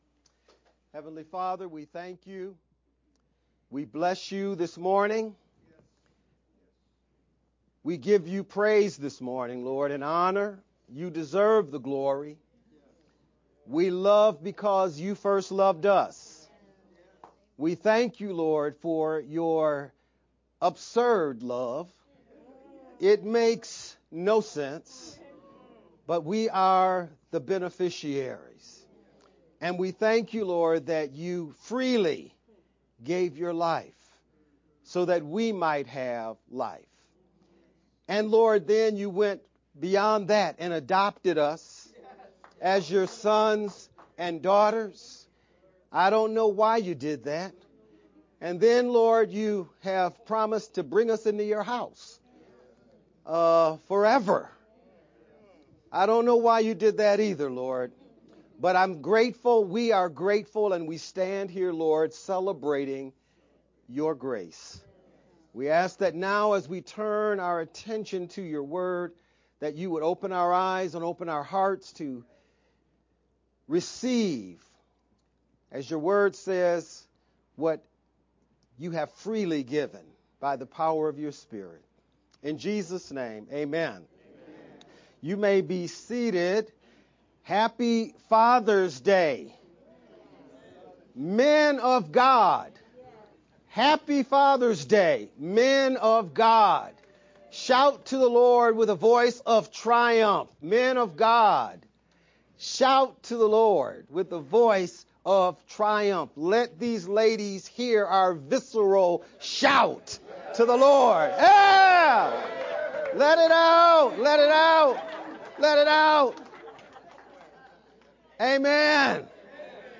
VBCC-Sermon-edited-only-June-15th_Converted-CD.mp3